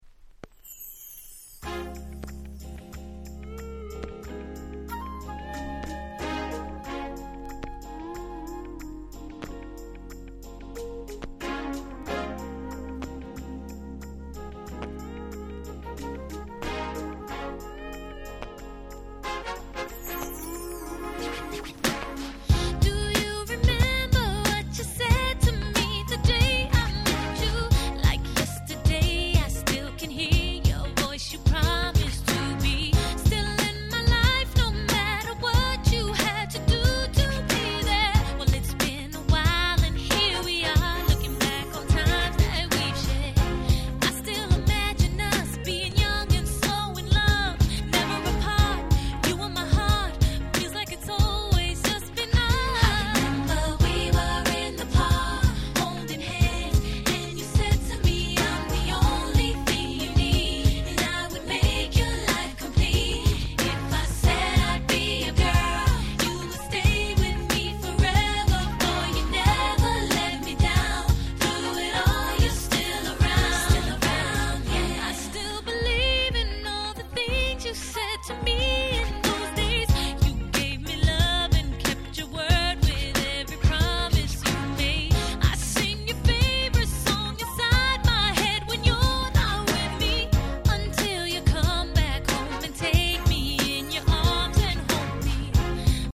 05' Super Hit R&B Album.